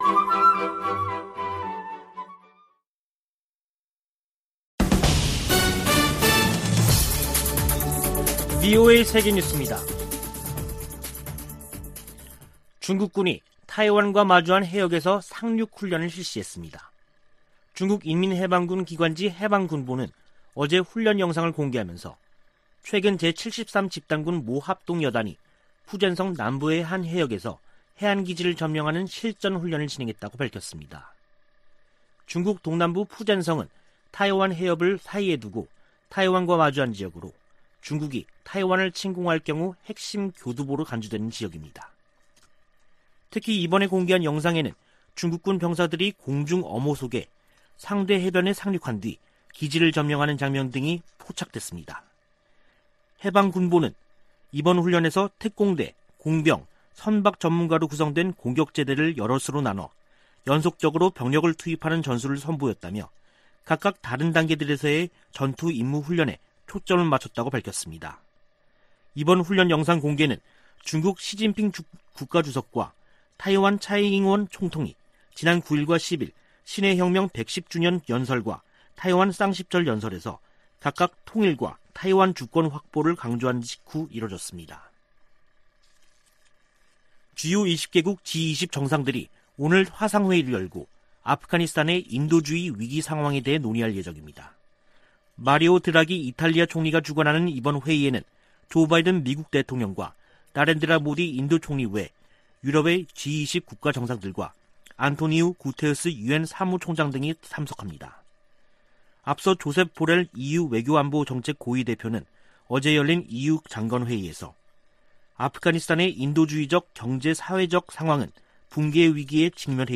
VOA 한국어 간판 뉴스 프로그램 '뉴스 투데이', 2021년 10월 12일 2부 방송입니다. 김정은 북한 국무위원장은 미국과 한국이 주적이 아니라면서도 핵무력 증강 지속 의지를 확인했습니다. 미국 정부의 대북정책을 지지하는 미국인이 작년보다 감소한 조사 결과가 나왔습니다. 옥스포드 사전에 '오빠' 등 한국어 단어 26개가 추가됐습니다.